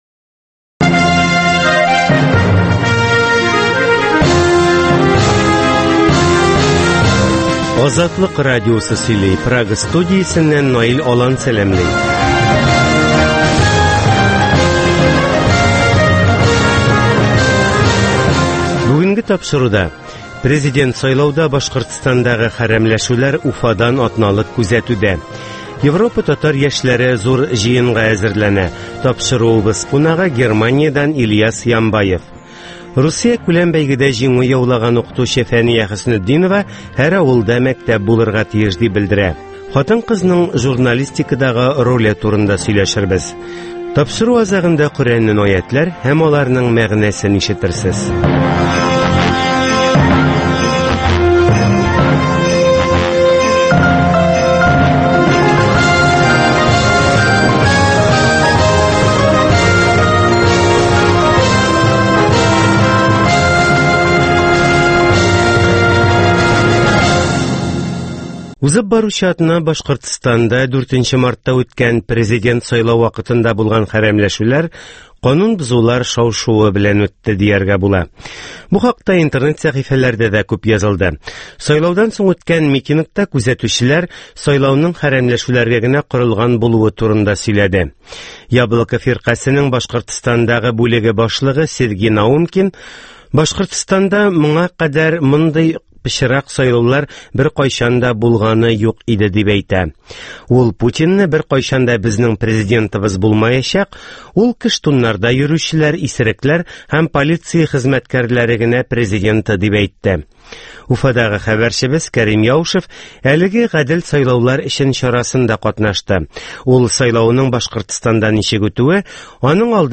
Азатлык узган атнага күз сала - Башкортстаннан атналык күзәтү - Татар дөньясы - Түгәрәк өстәл сөйләшүе - Коръәннән аятләр һәм аларның мәгънәсе